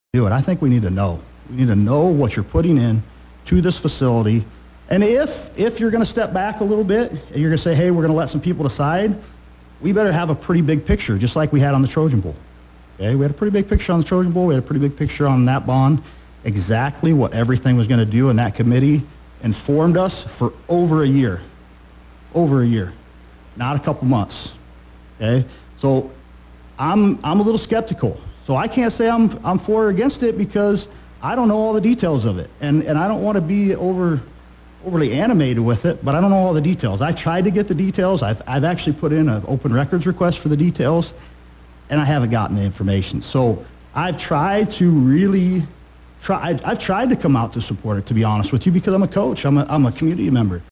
The bond issues highlighted Sunday’s Meredith Communications Meet Your Candidate’s Forum.